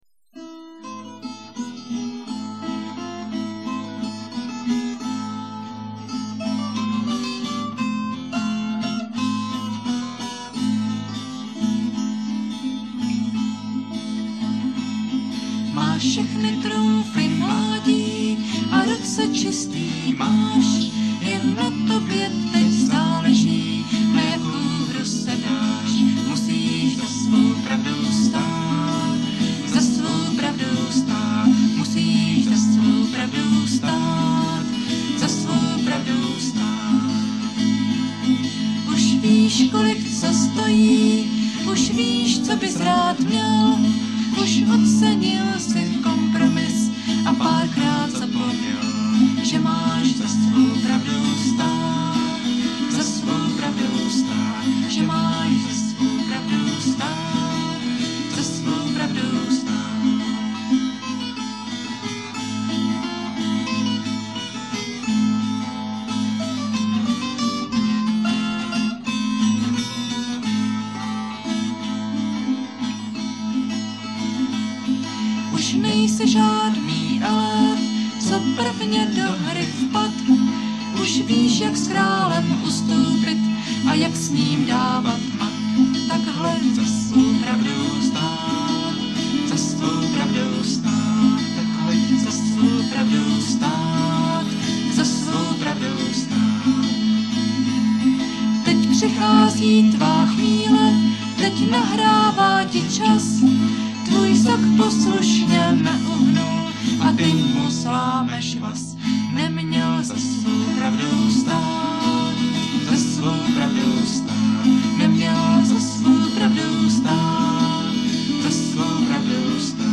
zpívejte na záznamy mp3 v podání 1 kytary a dvou hlasů